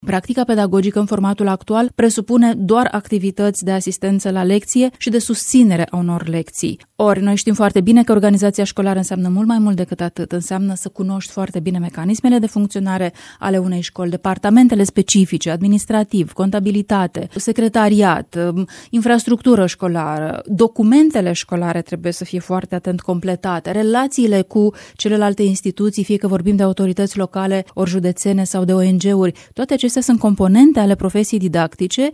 Adevărata urgență a sistemului de învățământ rămâne formarea cadrelor didactice, a declarat în emisiunea Dezbaterea Zilei,  consilierul de stat în cancelaria prim-ministrului,  Luciana Antoci.